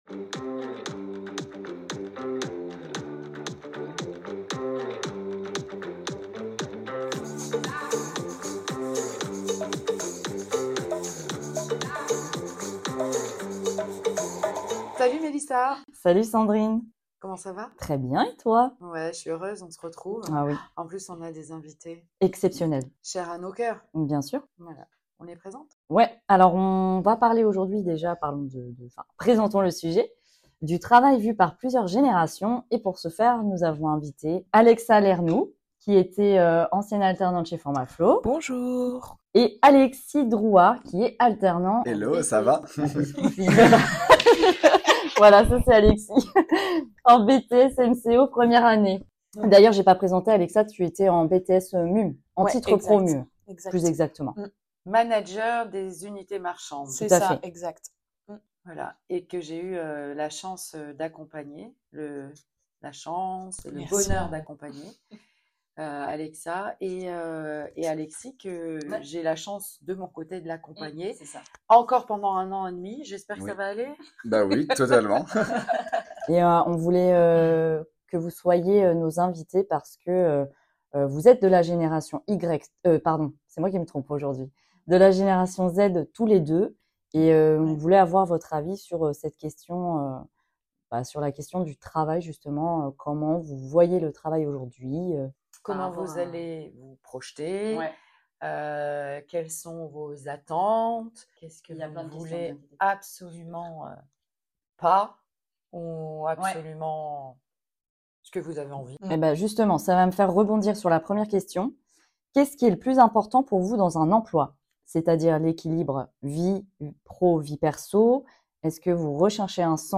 Nous avons accueilli deux jeunes talents de cette génération pour explorer leur vision du travail :